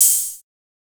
TS OpenHat_4.wav